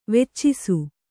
♪ veccisu